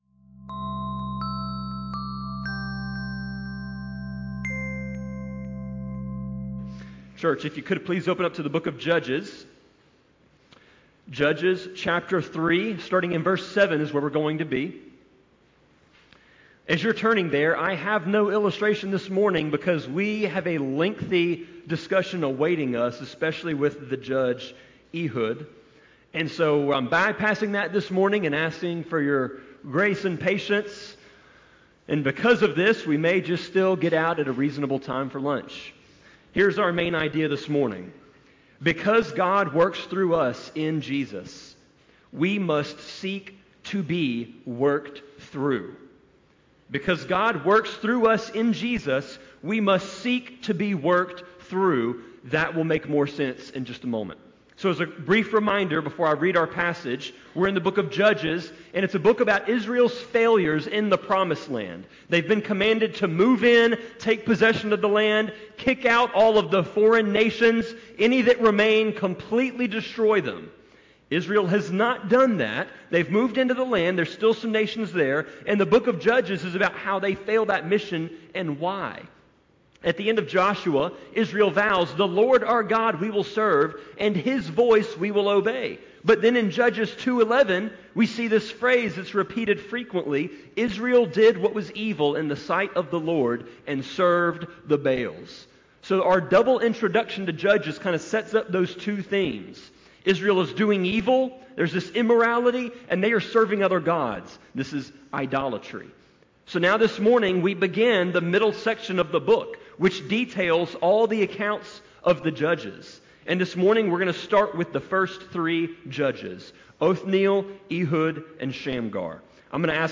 Sermon-25.9.21-CD.mp3